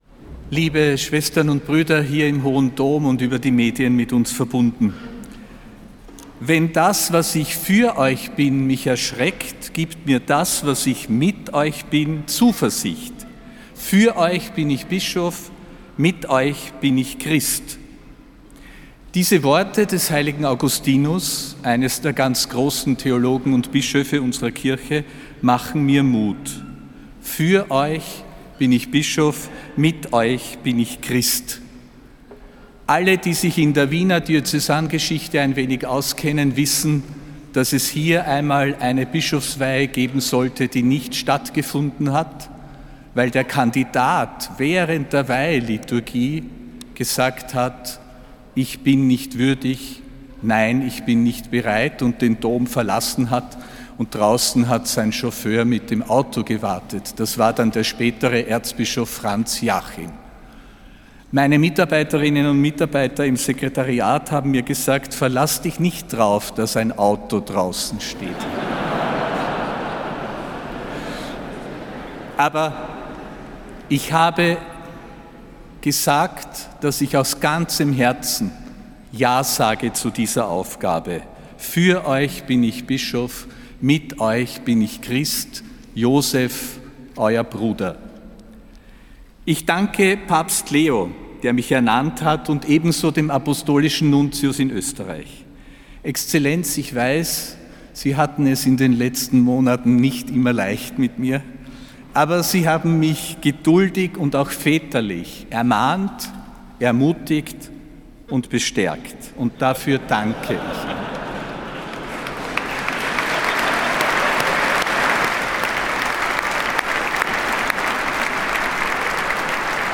Ansprache von Erzbischof Josef Grünwidl bei der Bischofsweihe, am 24. Jänner 2026.